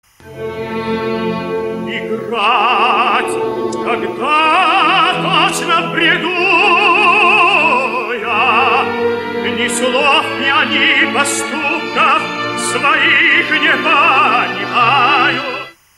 Оперы (1 500)
Правильный ответ: Руджеро Леонкавалло — «Паяцы», ария Канио